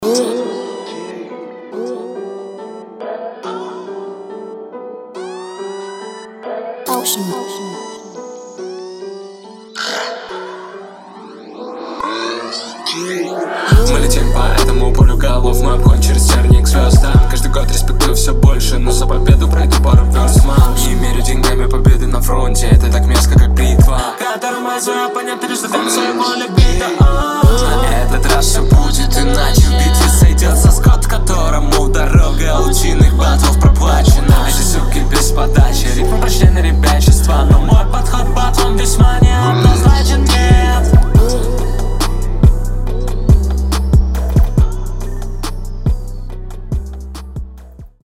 Но так стиль интересный, прикольно звучит.